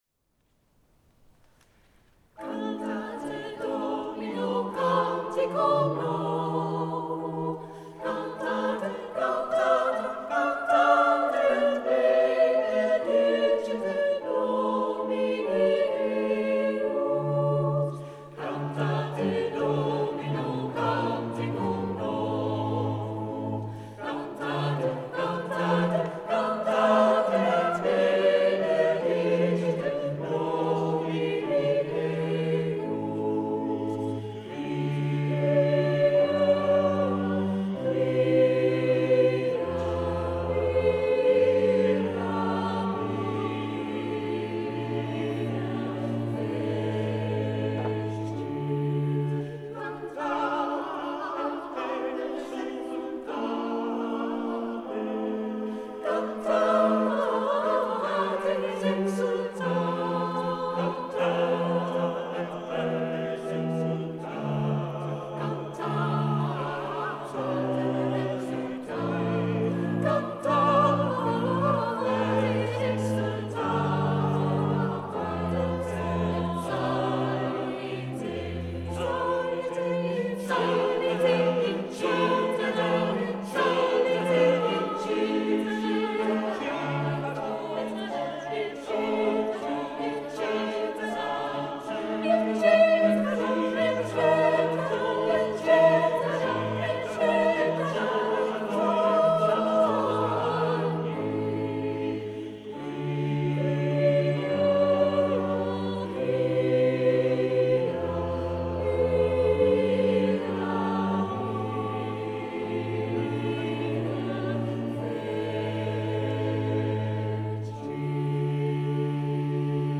Opnames van uitvoeringen door het SMC
Opnames in de St. Martinuskerk te Maastricht op 28 november 2015